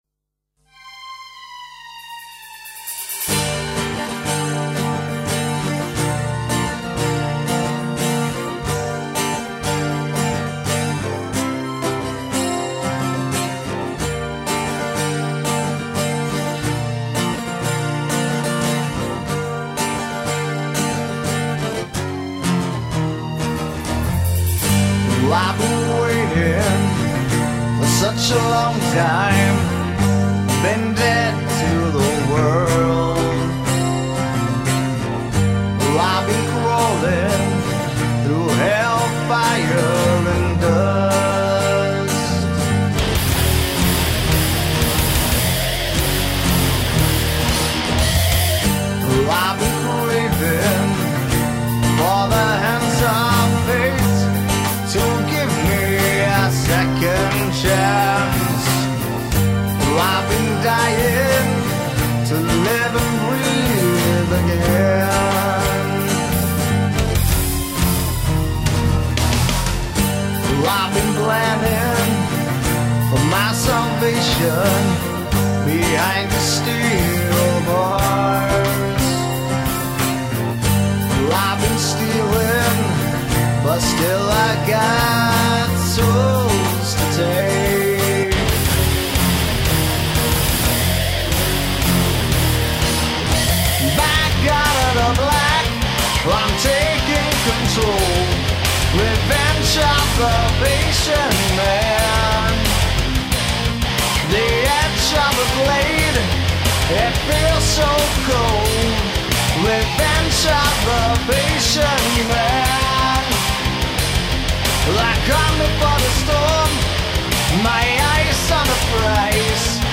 Vocals
Guitar
Bass,Drums